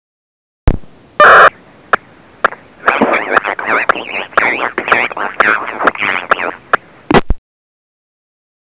Variable-band inversion inverts the signal around a constantly varying frequency, making decryption possible, but not bloody likely. Variable band inversion can be identified by the burst of modem noise at the beginning of the transmission (its a 1200 bps carrier) and the repeated clicking sounds as the inverting frequency changes.